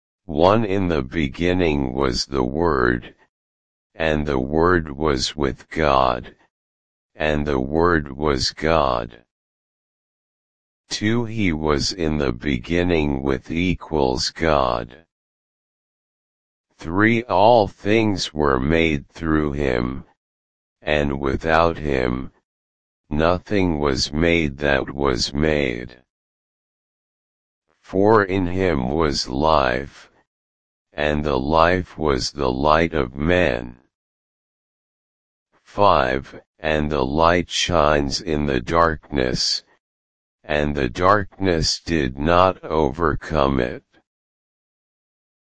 I have a tts “Change-to-110hz.mp3” speech file ,
That sounds pretty annoying! And the gaps between sentences are too long.
There is a slight bump at 75 Hz. 110/75 is about 1.47 and since it sounded slow to me, I used the Change Speed and Pitch effect (to change speed an pitch together) with a speed multiplier of 1.47. It sounded terrible!